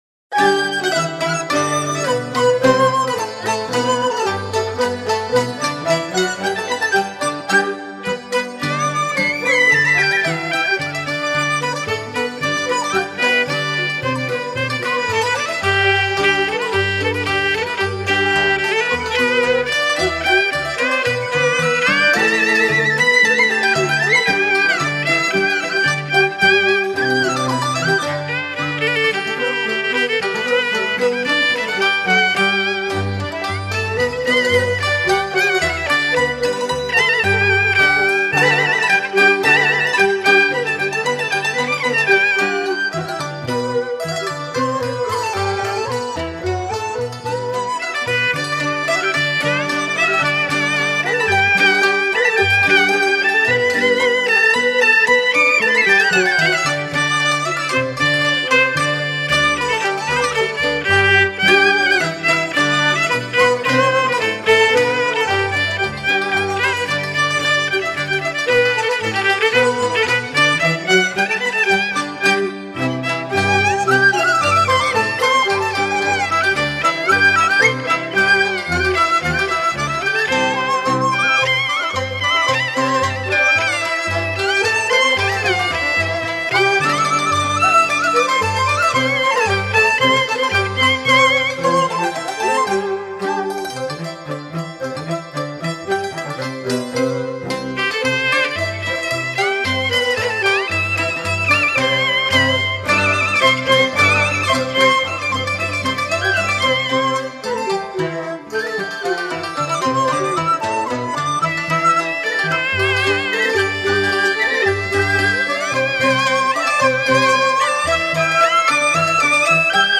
高胡演奏
高胡擅长演奏抒情、活泼、华丽的曲调。
洞箫 笛子
扬琴
二胡 椰胡 竹提琴
三弦 秦琴 中阮
大提琴
古筝
中胡